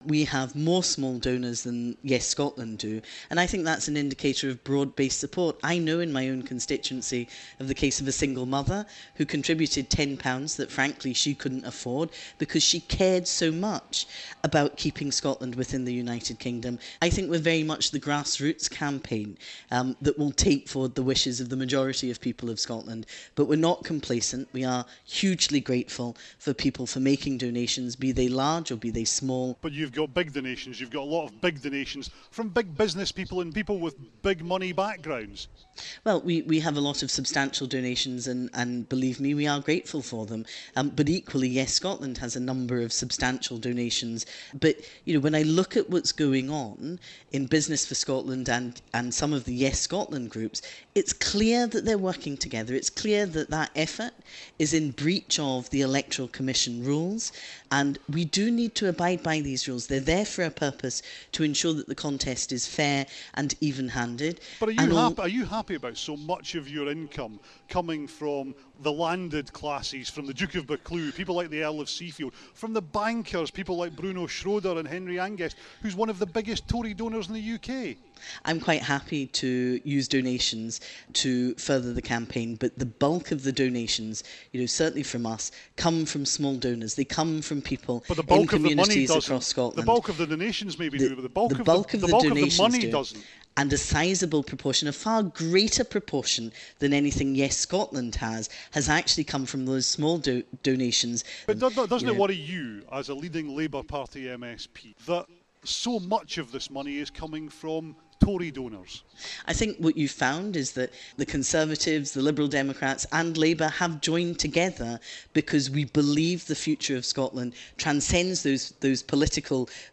Labour MSP and Better Together Director talking